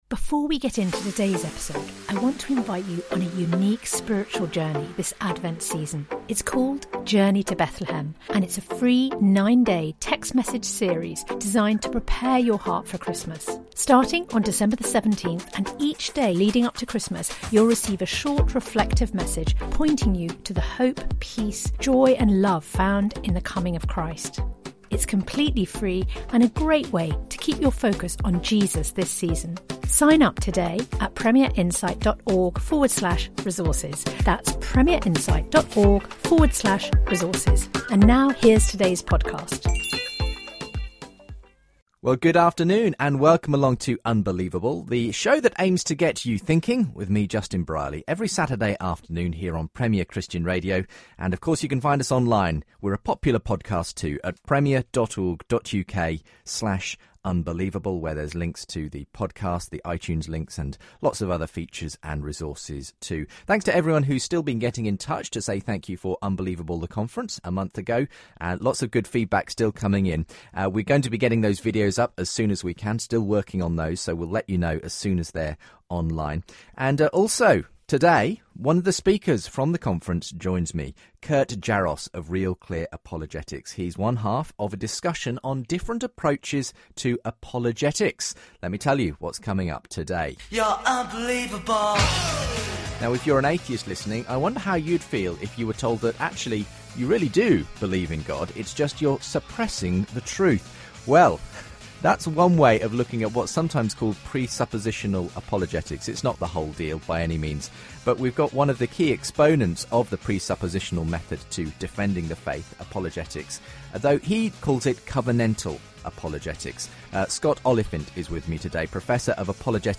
Christianity, Religion & Spirituality 4.6 • 2.3K Ratings 🗓 23 June 2014 ⏱ 81 minutes 🔗 Recording | iTunes | RSS 🧾 Download transcript Summary Two different approaches to apologetics are debated on the show.